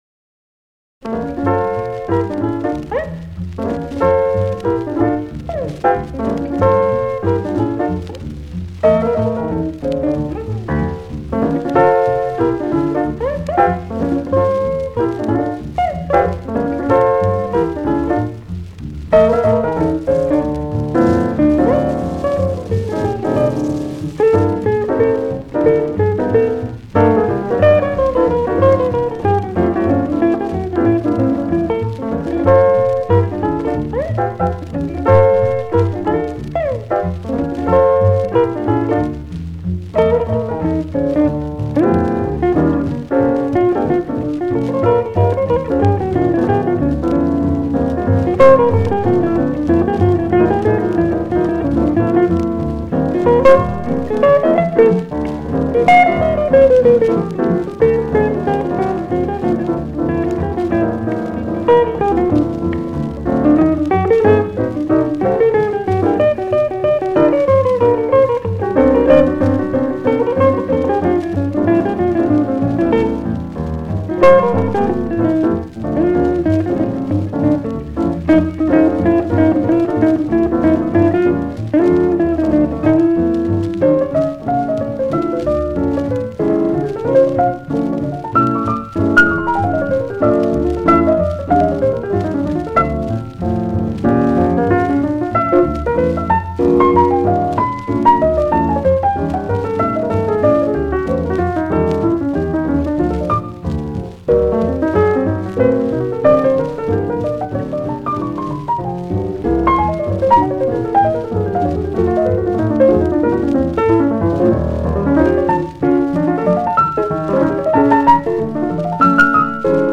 cruizy
guitar